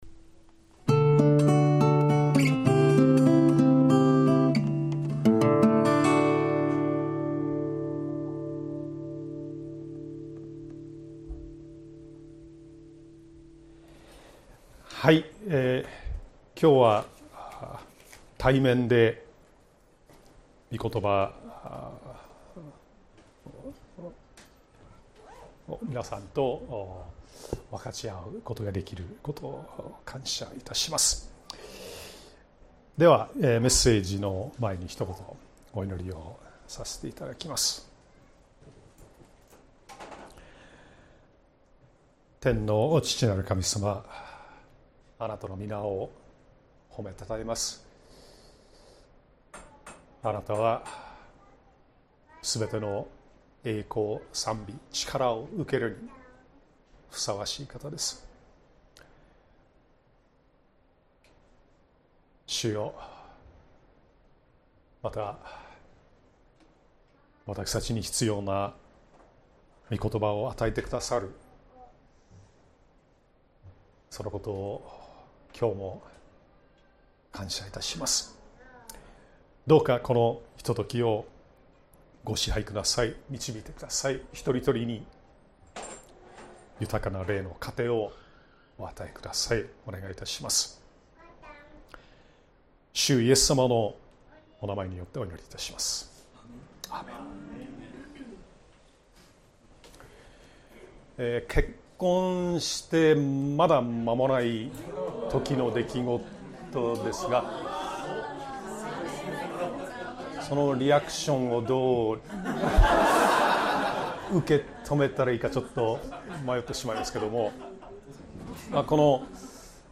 シリーズ: ゲストスピーカー 聖書箇所: マルコの福音書 5:21-43 « 心の牢獄からの解放